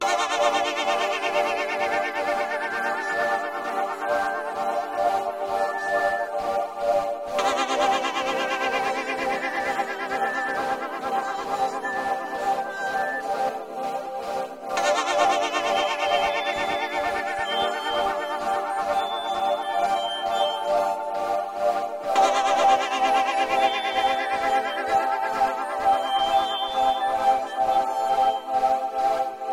描述：一个胖胖的模拟合成器补丁，演奏一些催眠的和弦。
Tag: 65 bpm Chill Out Loops Synth Loops 4.97 MB wav Key : C Sonar